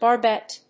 発音記号・読み方
/bɑˈrbɛt(米国英語), bɑ:ˈrbet(英国英語)/